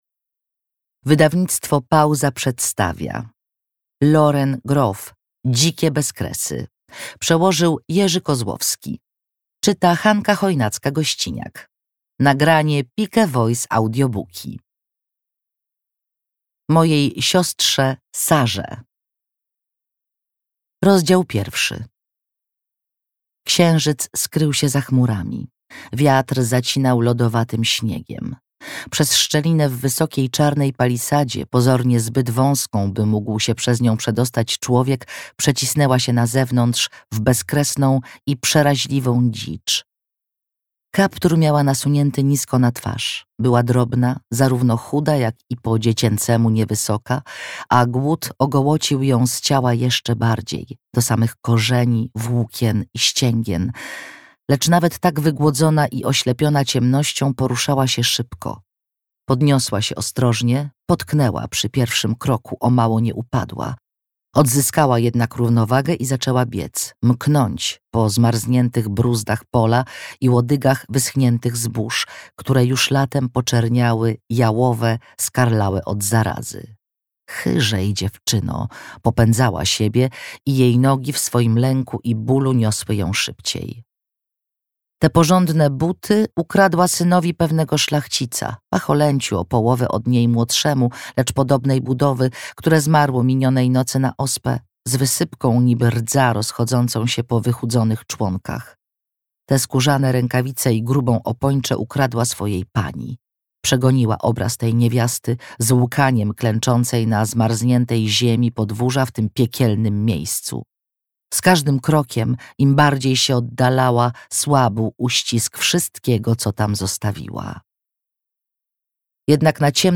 Dzikie bezkresy (audiobook) – Wydawnictwo Pauza
AUDIOBOOK